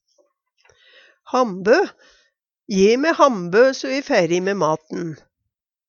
hambø - Numedalsmål (en-US)